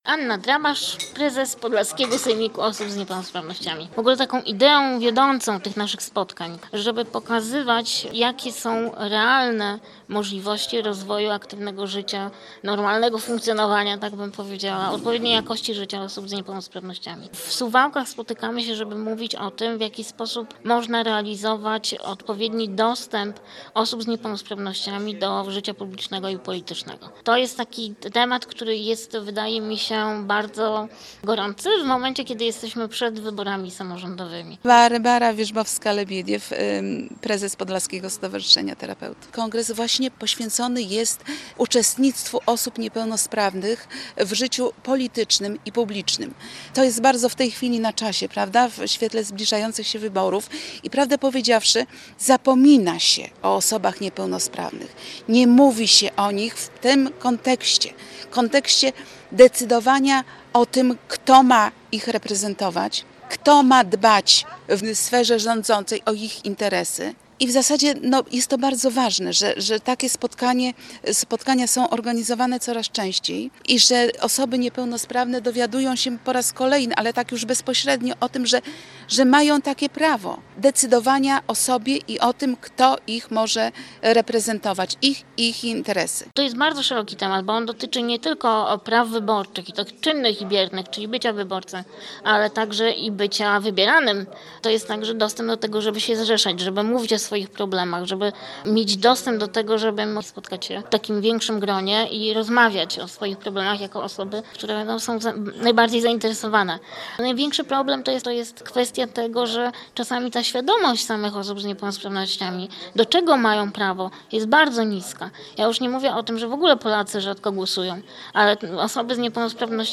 O udziale osób z niepełnosprawnościami w życiu politycznym i publicznym rozmawiali we wtorek, 11 września w Suwałkach przedstawiciele Podlaskiego Sejmiku Osób z Niepełnosprawnościami.
Mówią uczestnicy konwentu: